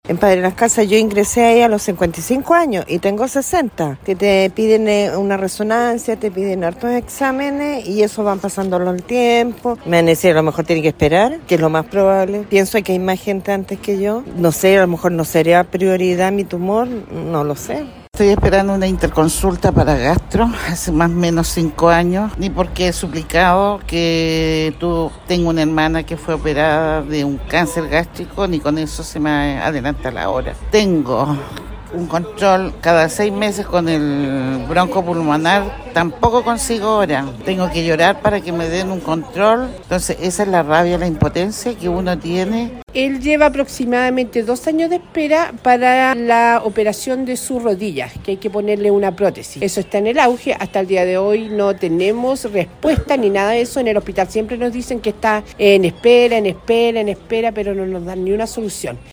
Radio Bío Bío en Temuco conversó con pacientes que llevan, en algunos casos, esperando por atención especializada. Así lo relató una mujer que aseveró estar esperando hace 5 años una consulta de gastroenterólogo. Otro hombre lleva dos años esperando la operación de rodilla y suma y sigue.